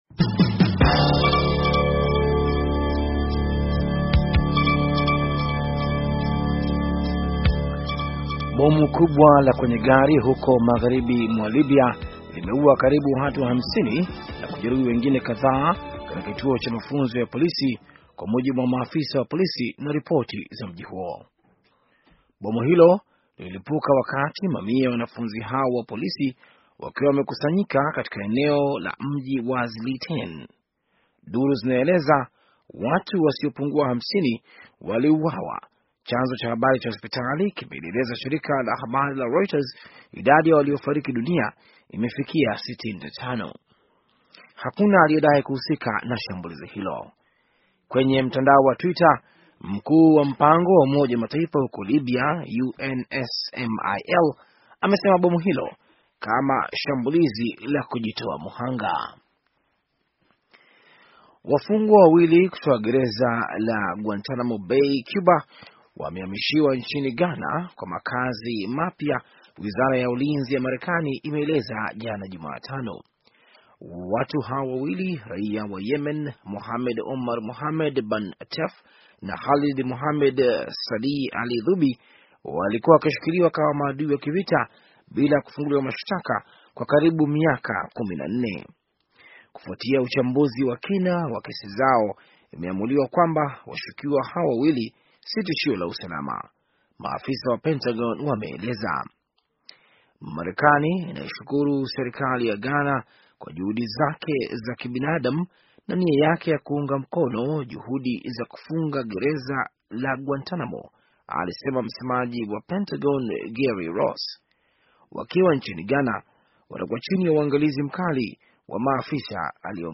Taarifa ya habari - 6:25